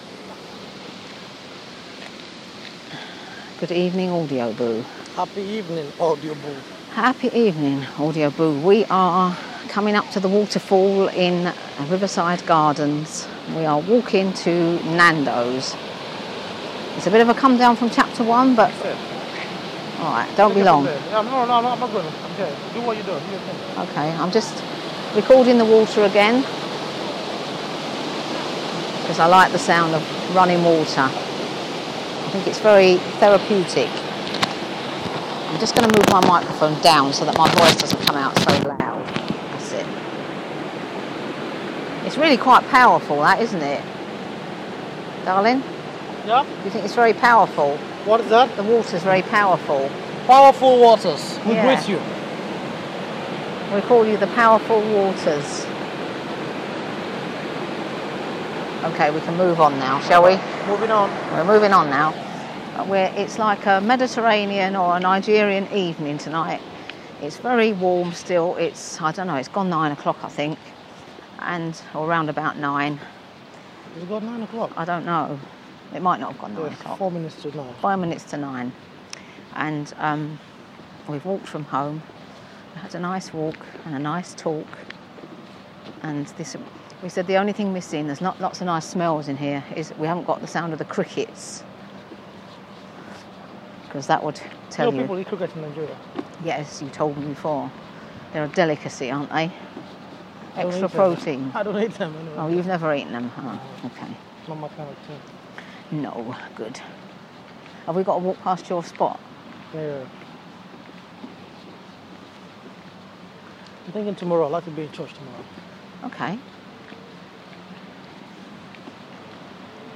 Headliner Embed Embed code See more options Share Facebook X Subscribe Share Facebook X Subscribe Next Recorded from my bedroom window, some sounds of Sunday evening, including soothing rain, a bit of thunder, and very little input from me!